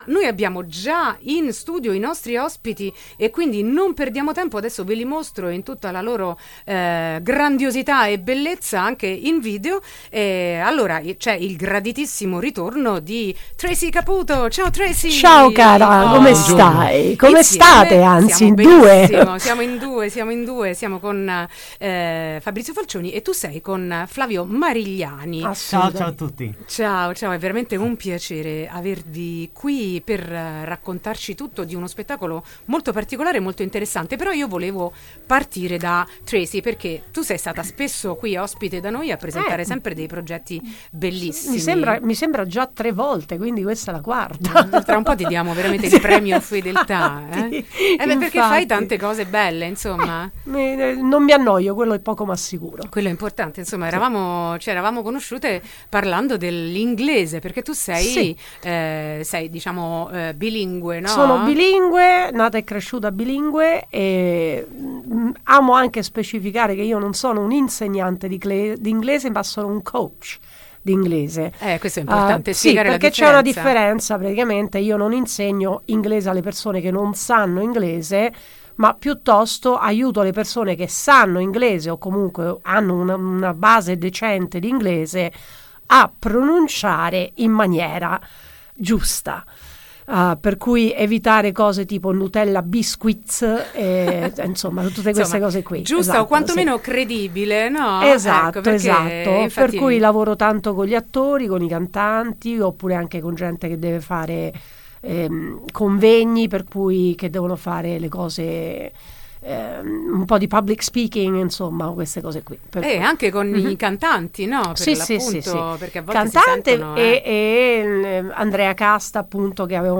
intervista-teatro-multilingue-6-12-24.mp3